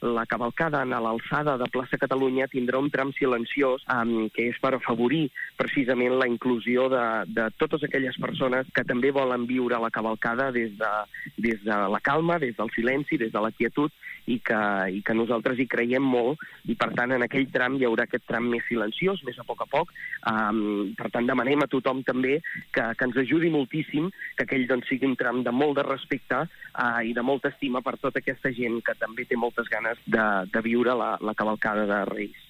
El tinent d’Alcaldia de Cultura, Josep Grima, ha parlat al matinal de Ràdio Calella TV, a la FM I +, de l’estrena de noves carrosses que representaran tant la identitat de la ciutat com de Ses Majestats.